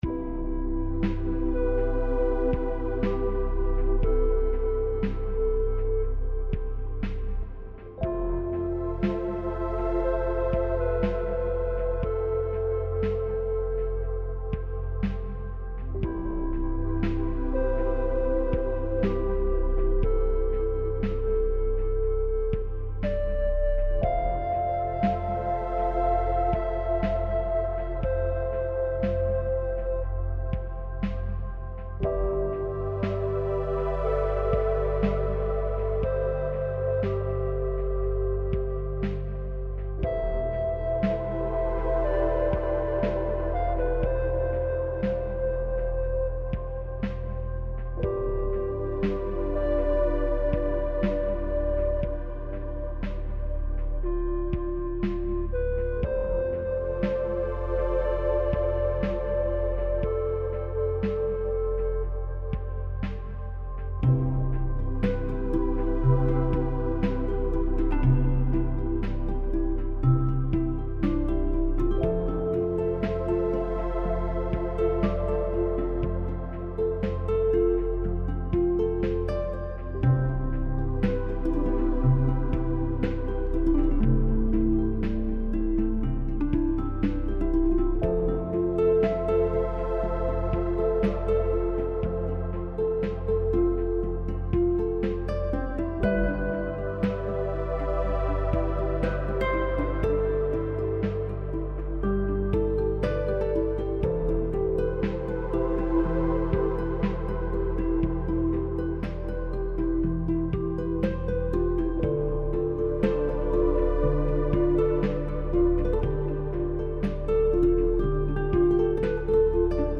Relaxing Synth Music